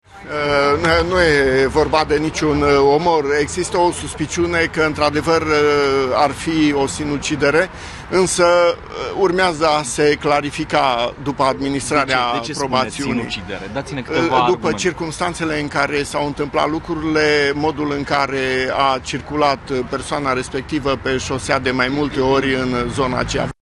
În această dimineață, el le-a declarat jurnaliștilor că mult mai probabilă ar fi varianta sinuciderii, după cum arată indiciile colectate de anchetatori de la locul accidentului: